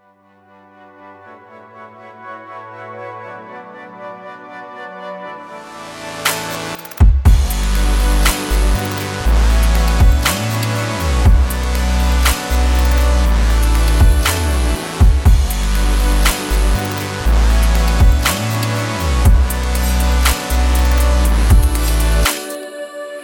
ambience-4.ogg